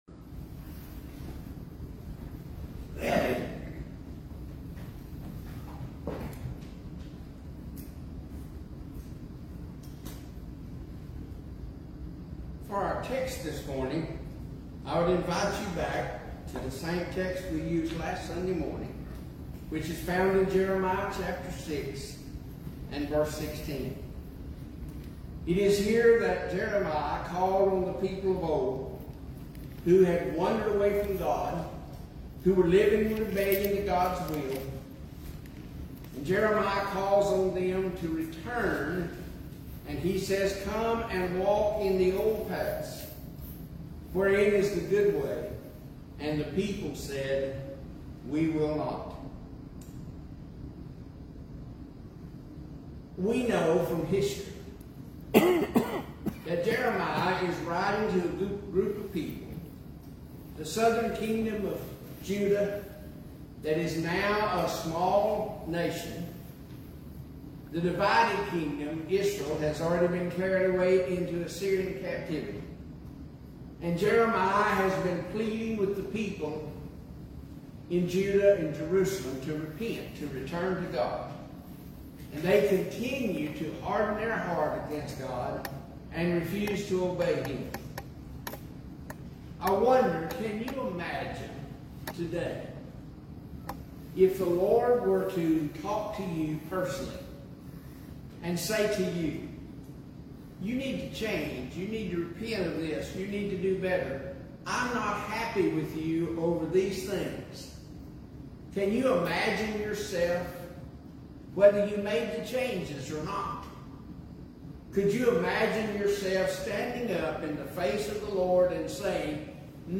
6-8-25-Sunday-AM-Sermon-Waldo-Audio.mp3